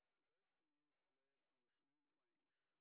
sp01_exhibition_snr10.wav